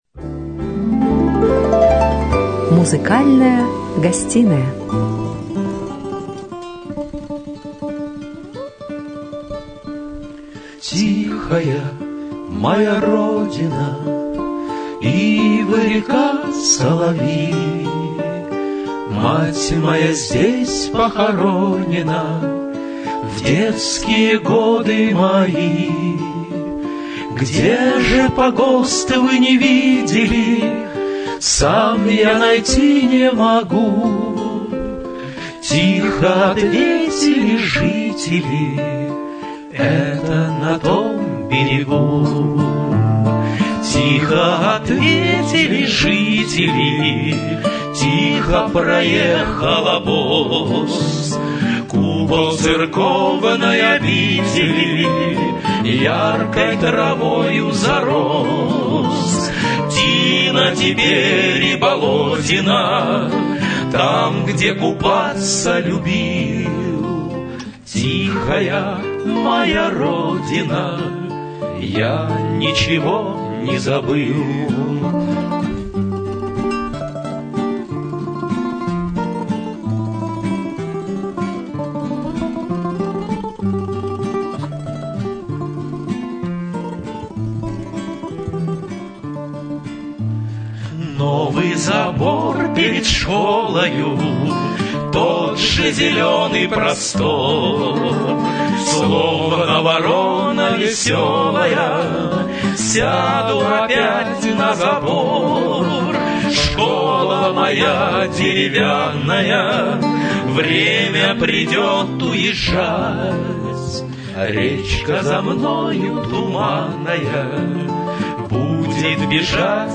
Запись беседы с музыкантами и фрагментов концертной программы